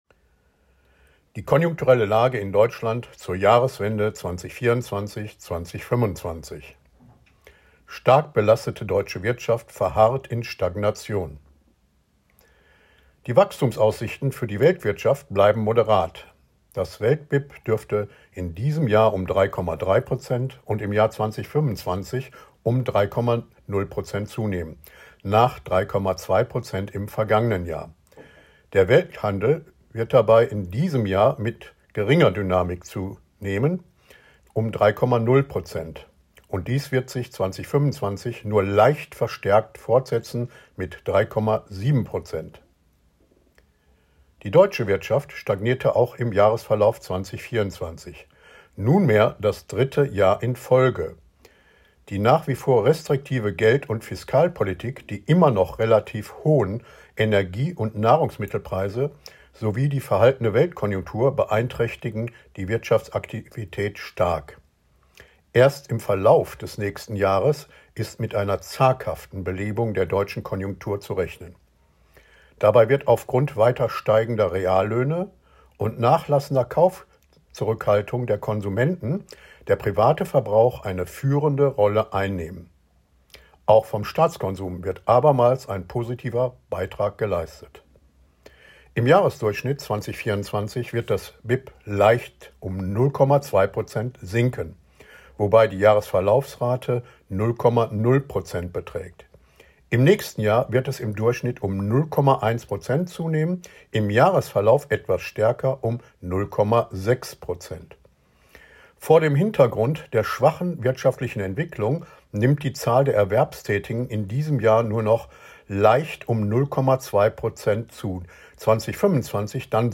IMK Report 193: Audio-Statement zur konjunkturellen Lage in Deutschland zur Jahreswende